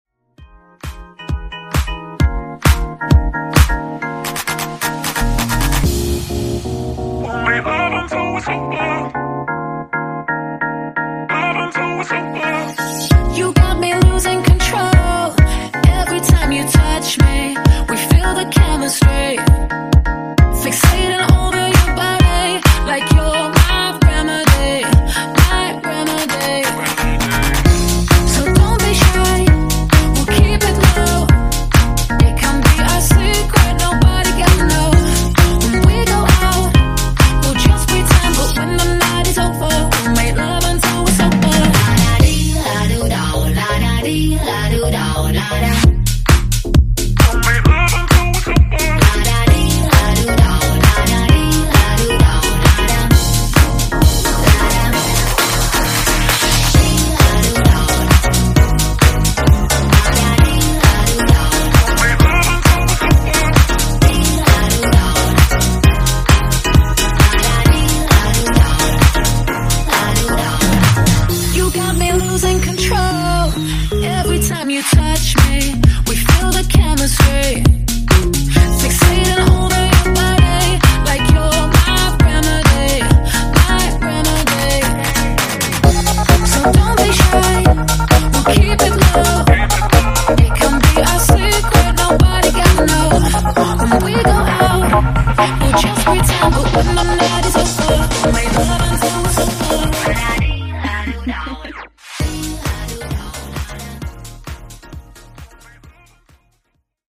Genre: RE-DRUM
Dirty BPM: 96 Time